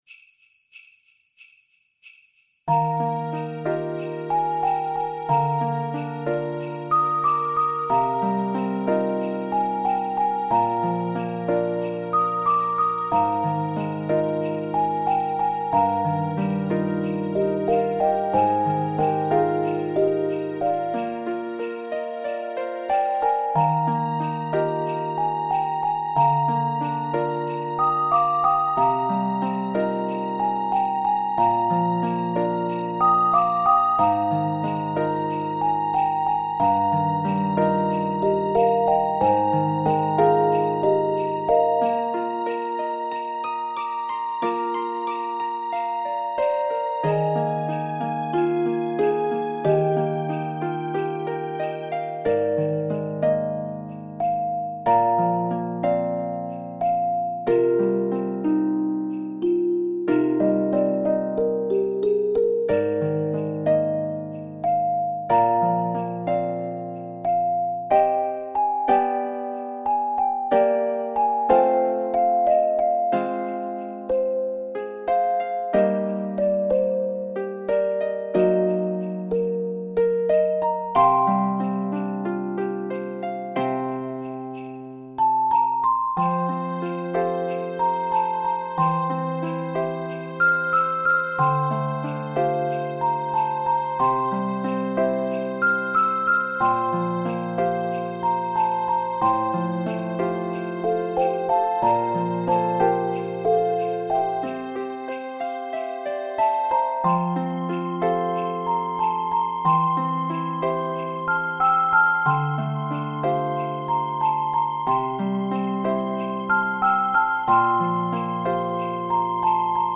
オルゴール曲素材